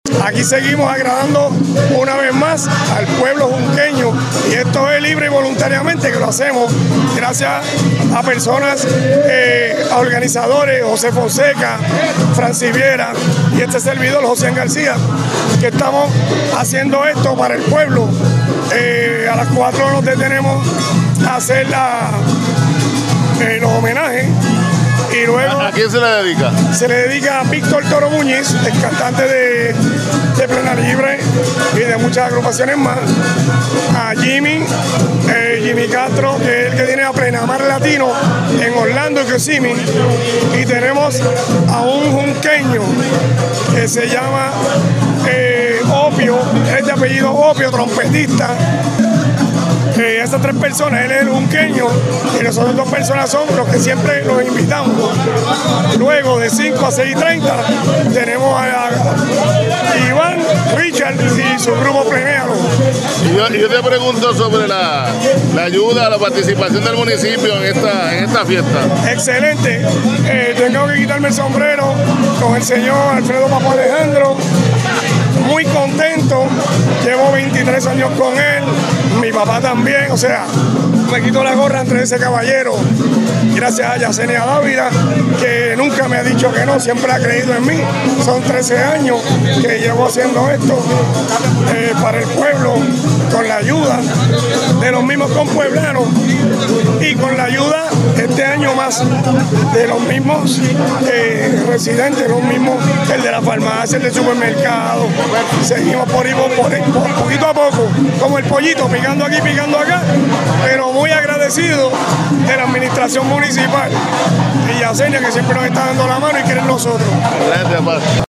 JUNCOS, 21 de febrero De 2026) En una tarde marcada por el orgullo cultural y la unidad comunitaria, el Municipio Autónomo de Juncos celebró con gran éxito la decimotercera edición del Plenazo Junqueño, convirtiendo la Plaza de Recreo Antonio R. Barceló en el epicentro de la tradición puertorriqueña este sábado, 21 de febrero.